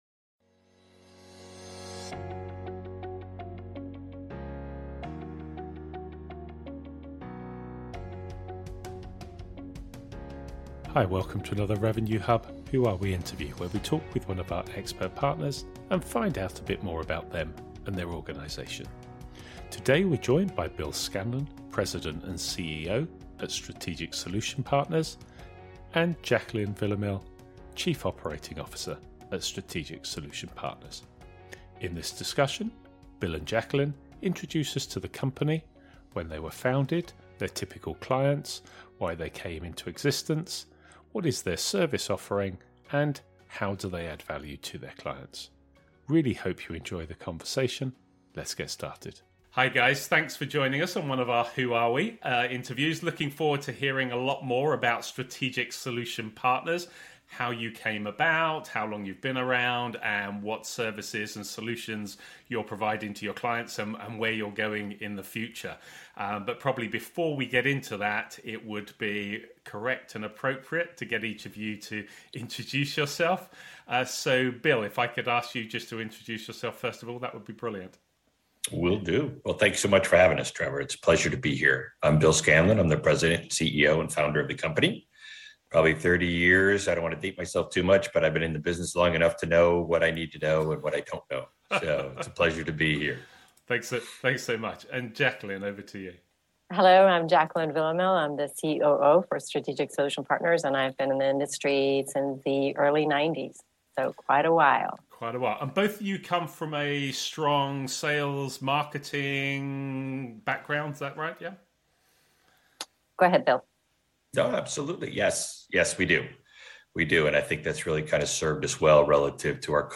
In this "Who Are We" interview we are joined by: